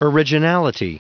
Prononciation du mot originality en anglais (fichier audio)
Prononciation du mot : originality